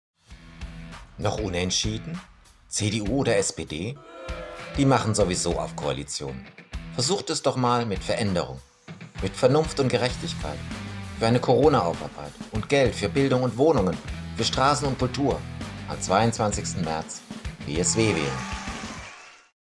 Wahlwerbespots Hörfunk